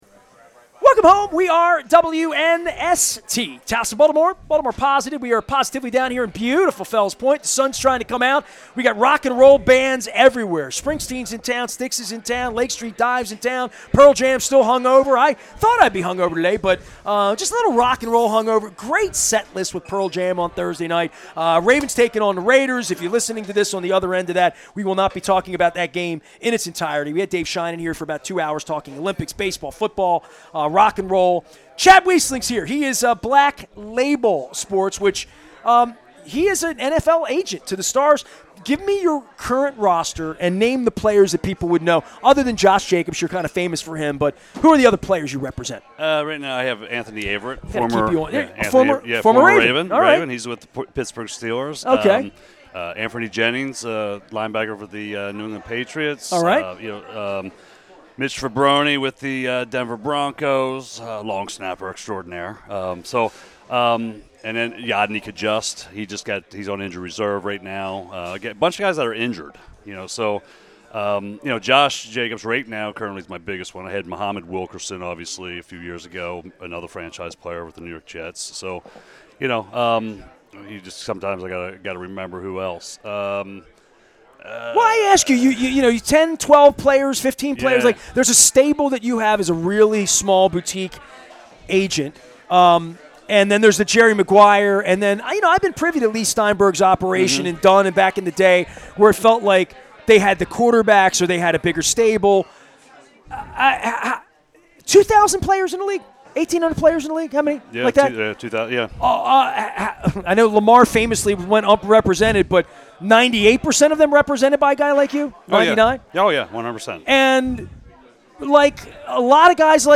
his players at heart of his work and why he loves the Baltimore Orioles so much on the Maryland Crab Cake Tour at Kooper's Tavern in Fells Point at Oyster Fest.